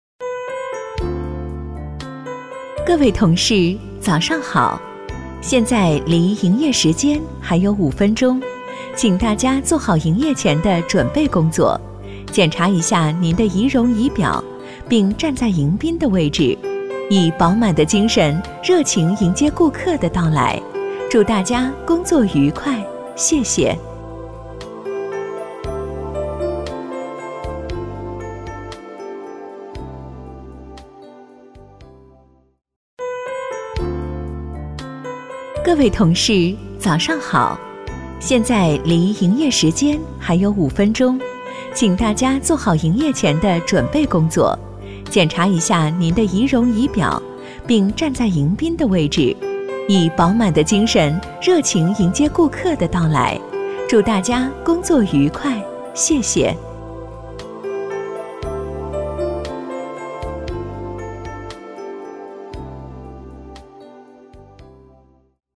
• 女S12 国语 女声 语音播报 商场广播-甜美 积极向上|时尚活力|亲切甜美